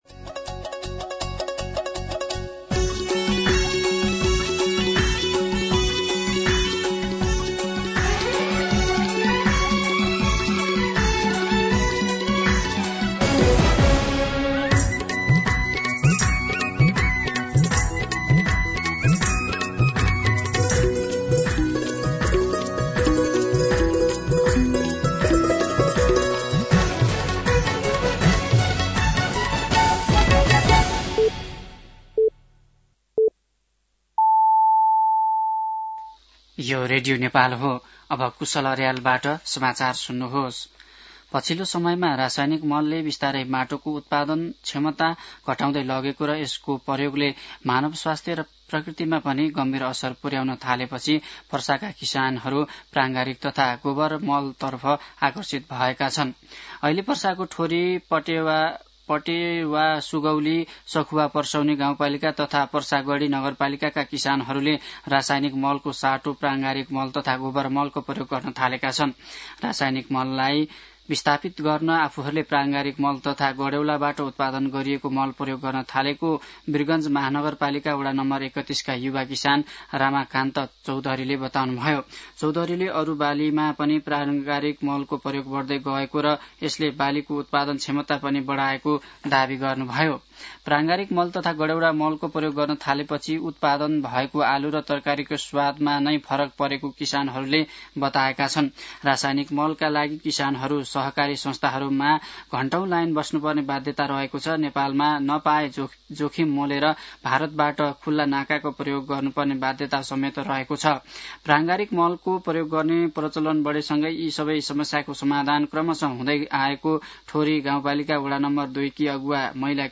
An online outlet of Nepal's national radio broadcaster
दिउँसो १ बजेको नेपाली समाचार : १३ मंसिर , २०८२
1-pm-Nepali-News-5.mp3